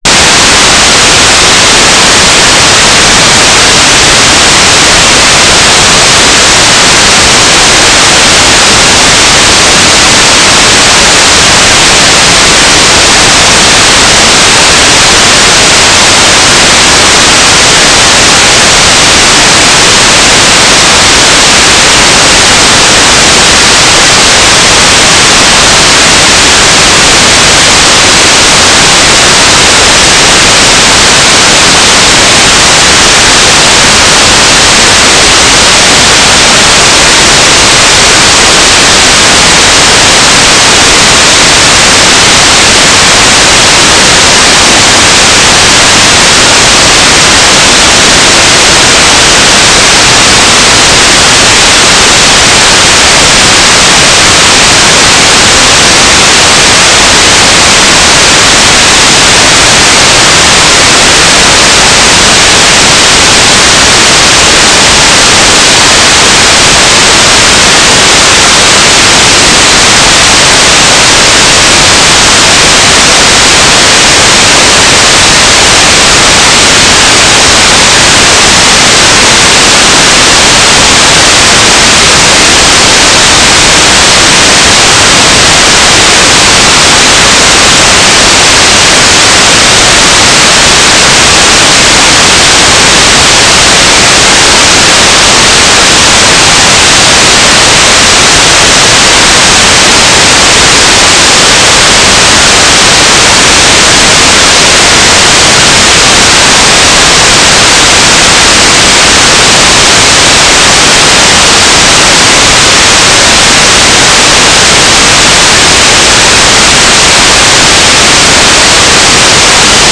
"transmitter_description": "Mode U - GMSK9k6 AX.25 G3RUH",
"transmitter_mode": "GMSK",